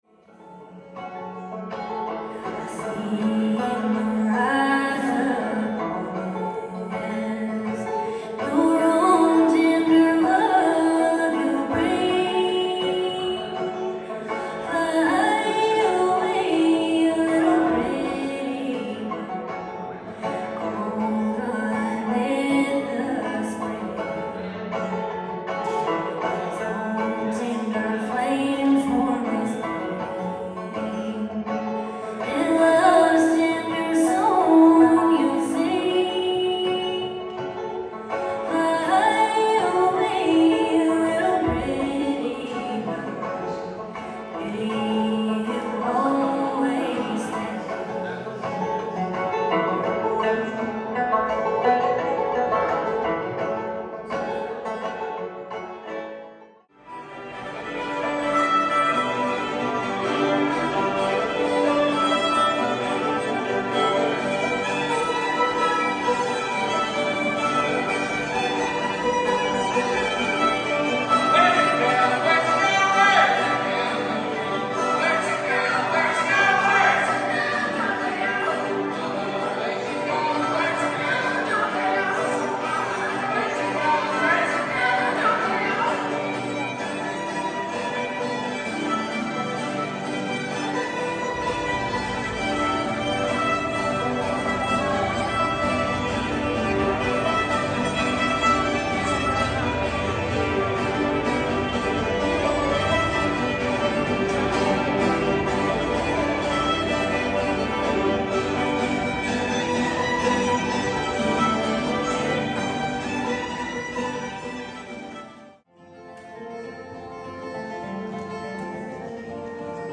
To enjoy an accompanying track of selected audio recordings from the Convention as you peruse the image gallery, click